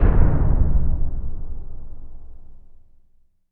LC IMP SLAM 2B.WAV